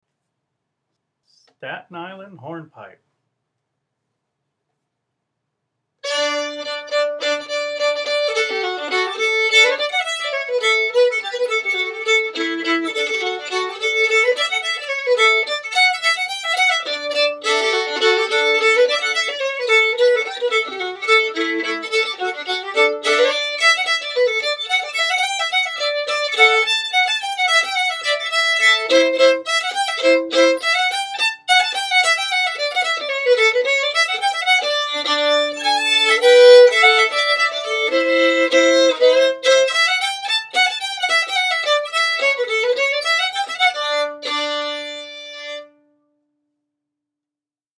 Set 4: Songs 61-117 (Advanced Arrangements)